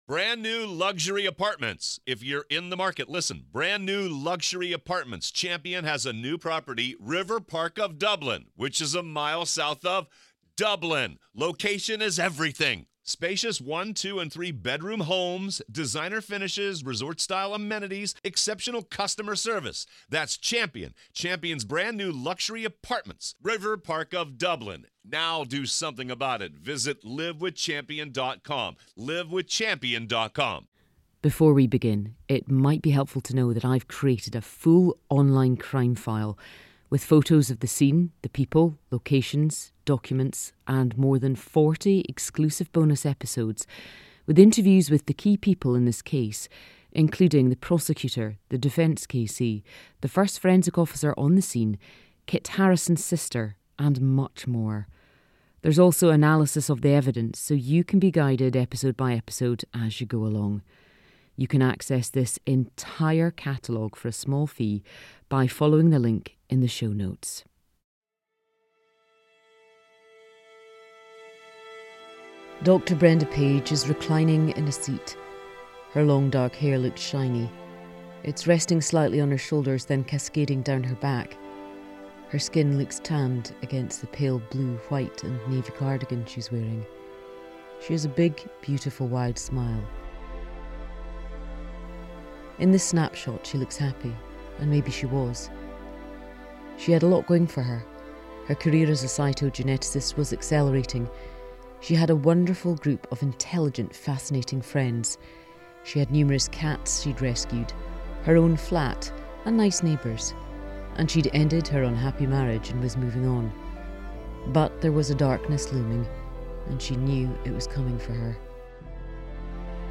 True Crime